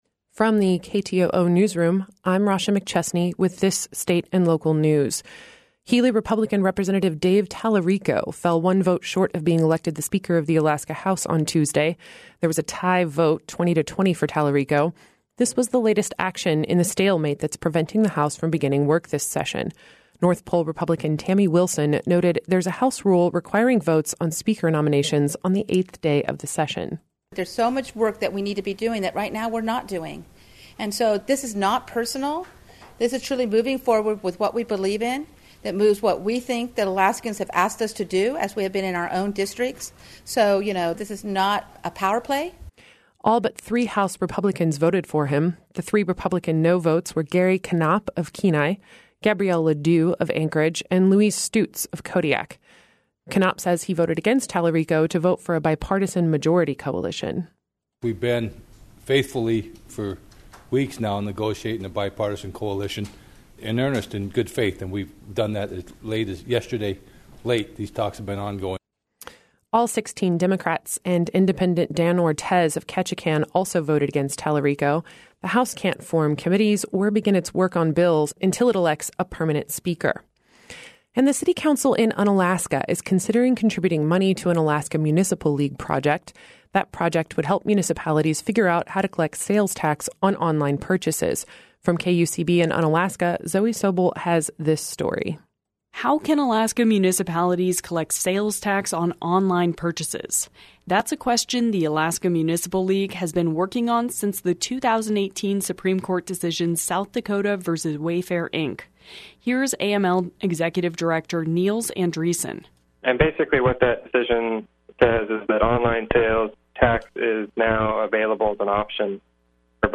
Newscast – Tuesday, January 22, 2019